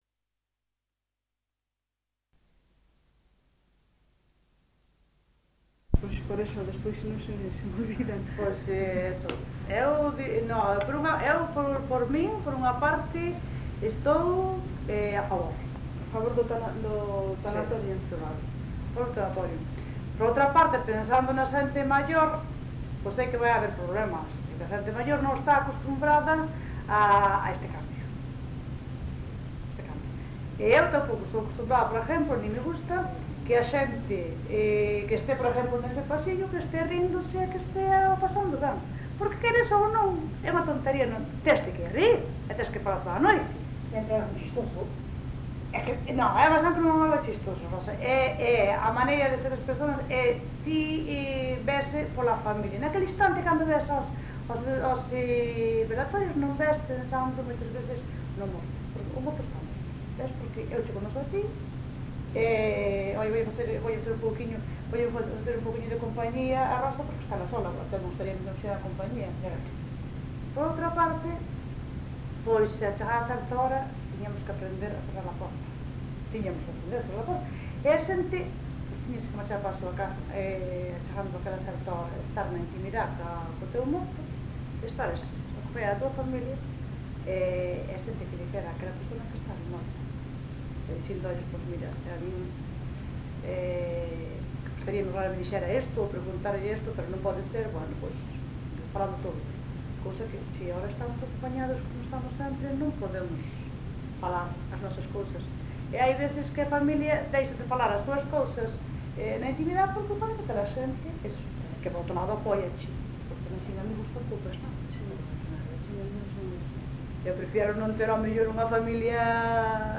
Tipo de rexistro: Etnográfico xeral
Soporte orixinal: Casete